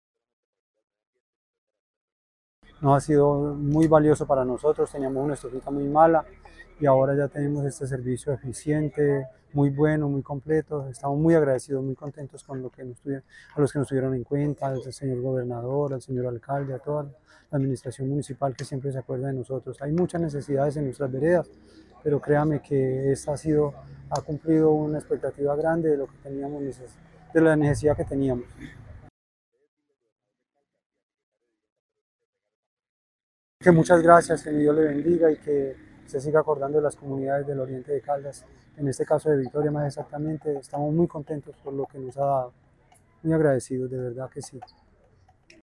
beneficiario del municipio de Victoria.